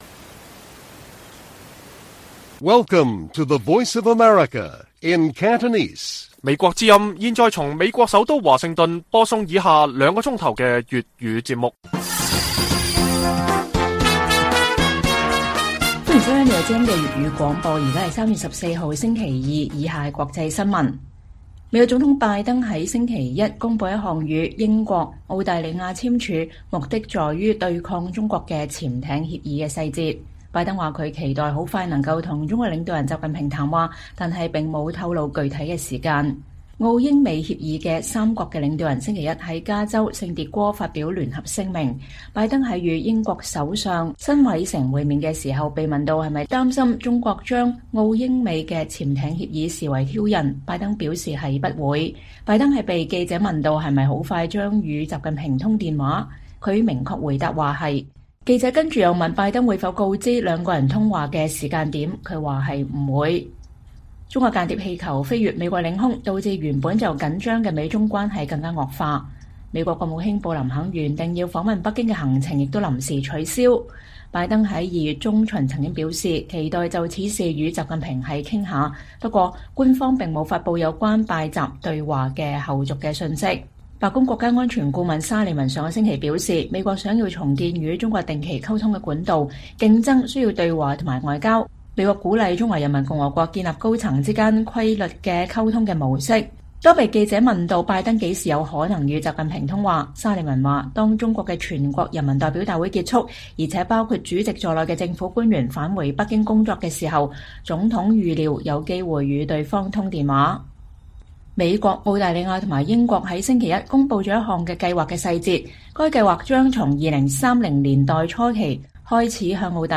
粵語新聞 晚上9-10點: 英國國防外交策略文件指中國為“劃時代挑戰”